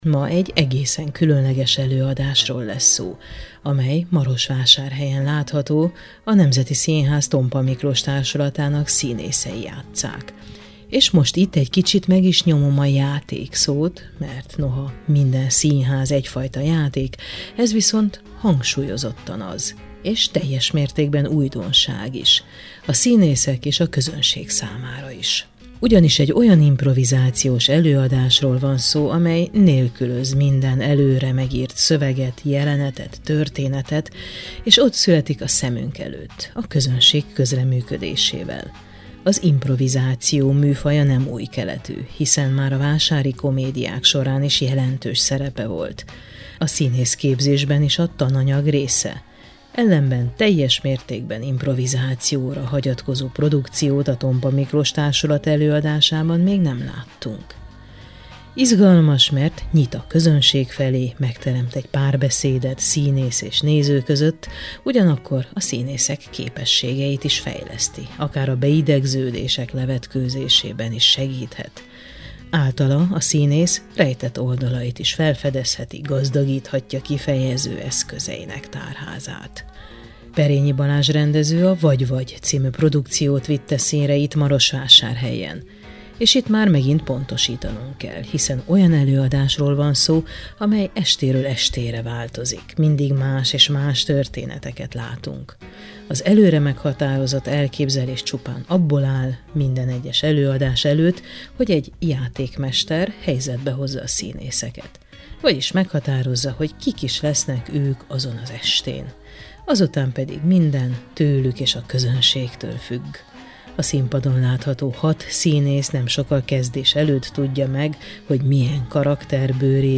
Erről a produkcióról beszélgetünk az alábbi műsorban